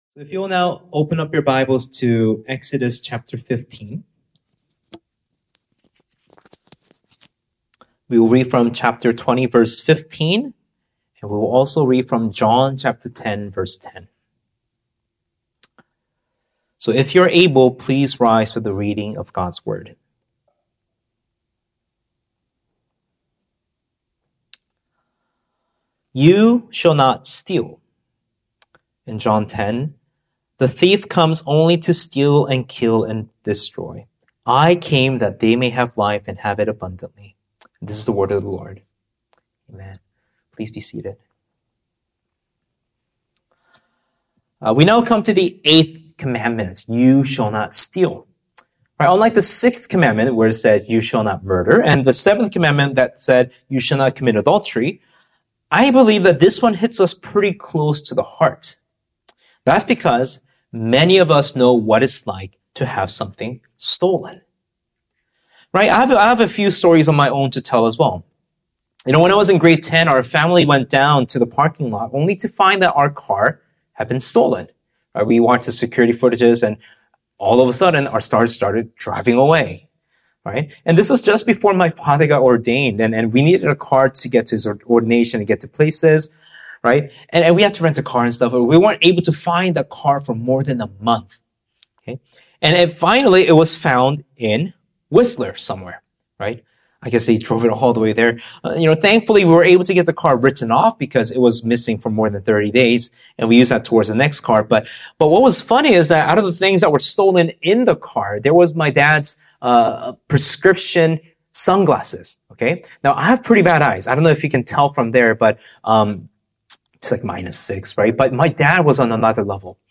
Sermons | Sonflower Community Church